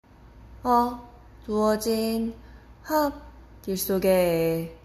click to listen to the pronunciation)